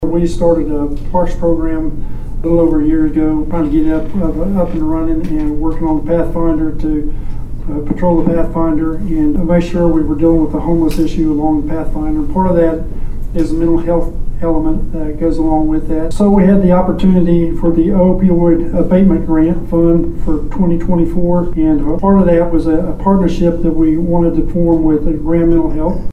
Police Chief Kevin Ickleberry
explains the grant opportunity.
Bartlesville City Council meeting.